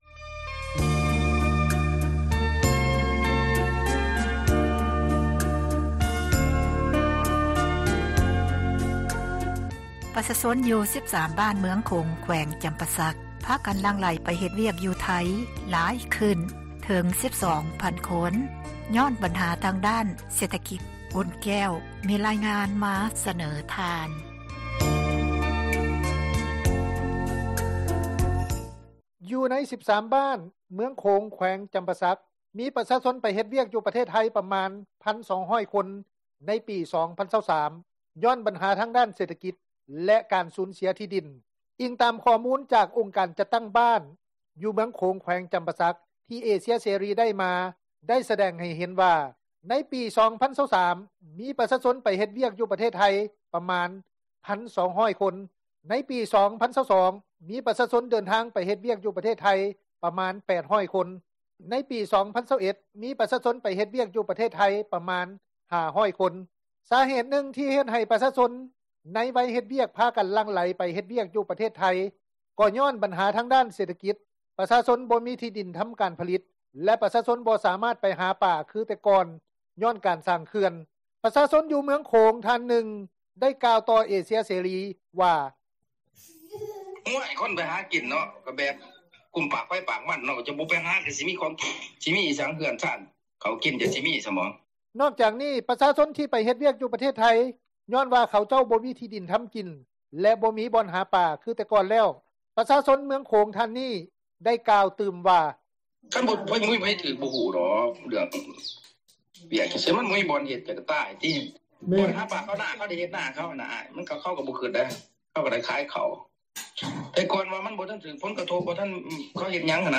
ປະຊາຊົນ ຢູ່ເມືຶອງໂຂງ ທ່ານນຶ່ງ ໄດ້ກ່າວຕໍ່ວິທຍຸ ເອເຊັຽເສຣີ ວ່າ:
ນັກທຸຣະກິຈ ທ່ານນຶ່ງ ຢູ່ພາກໃຕ້ຂອງລາວ ໄດ້ກ່າວຕໍ່ວິທຍຸ ເອເຊັຽເສຣີ ວ່າ: